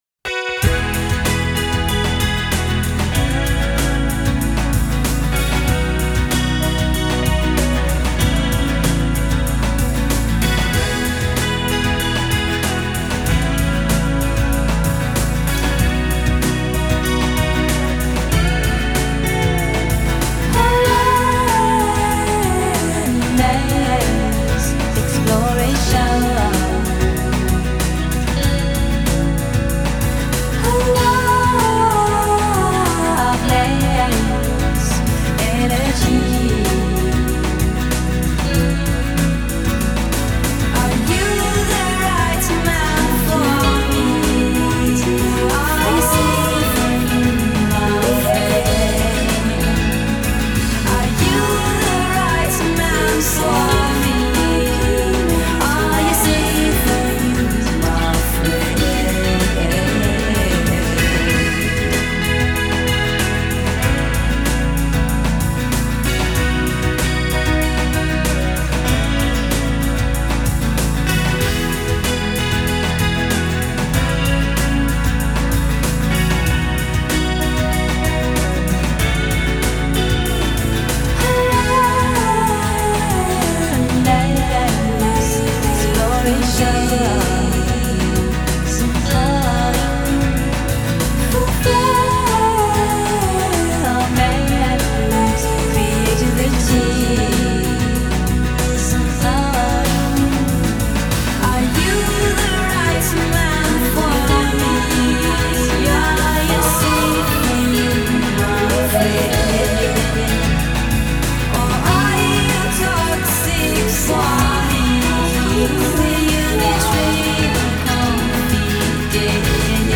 Dream pop
Дрим поп